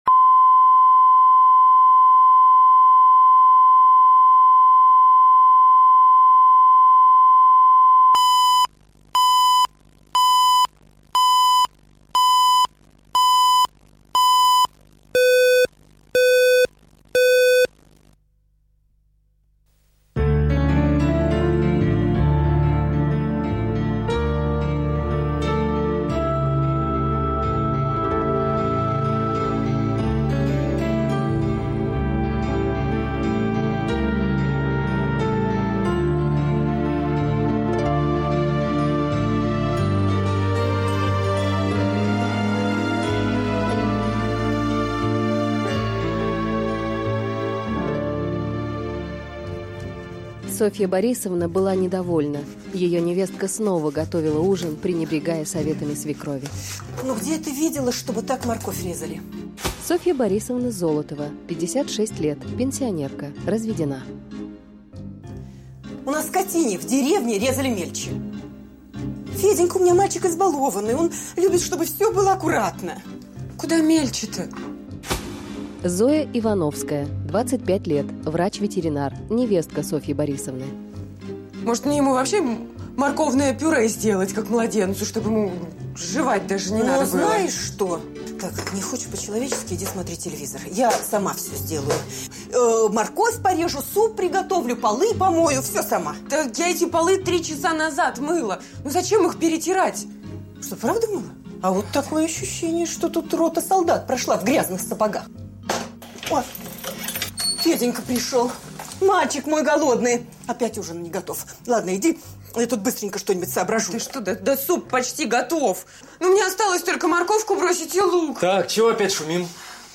Аудиокнига Две невестки | Библиотека аудиокниг